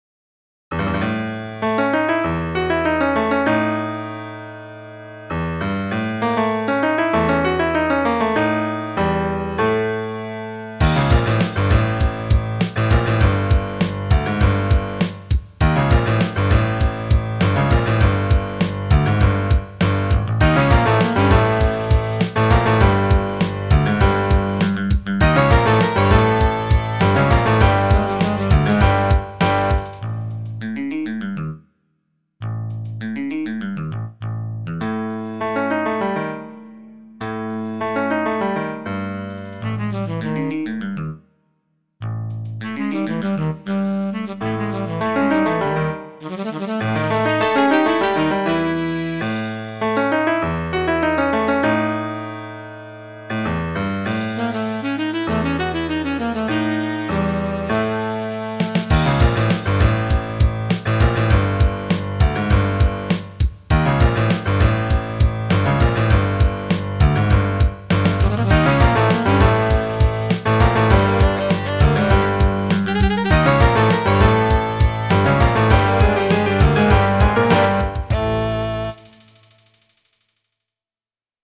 Influenced by Mahavishnu Orchestra, King Crimson, and the like. Maybe I should have saved this for Halloween; it's kind of sinister.
PROGRESSIVE ROCK MUSIC